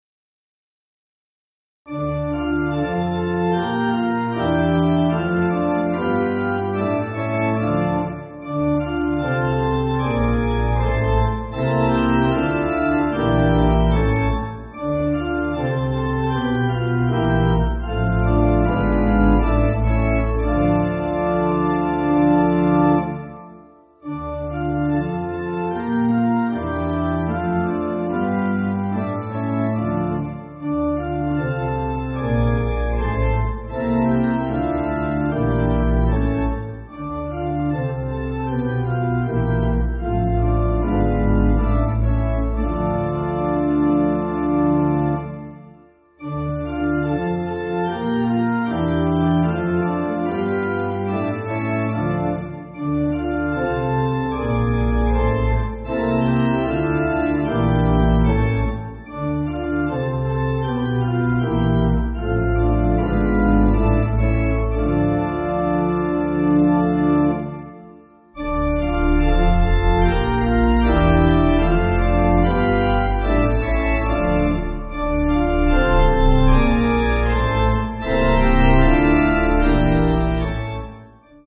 French folk tune
Organ
(CM)   4/Dm